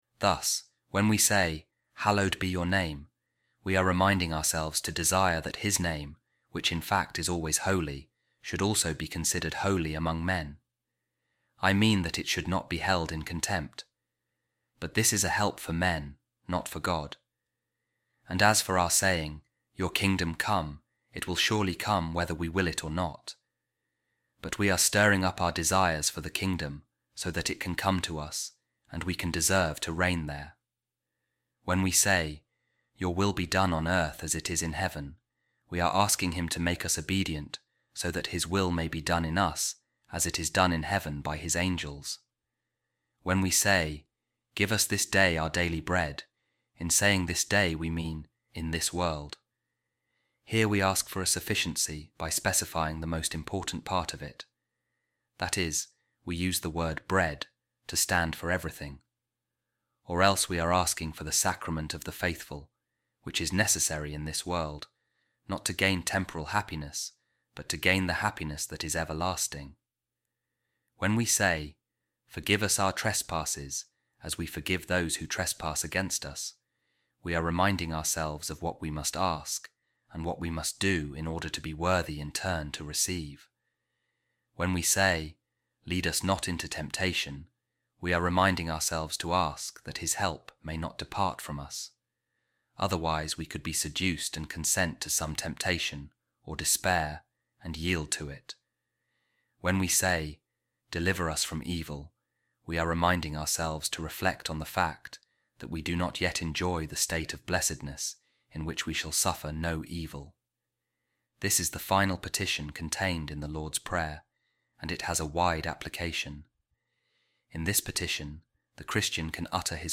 A Reading From The Letter Of Saint Augustine To Proba | On The Lord’s Prayer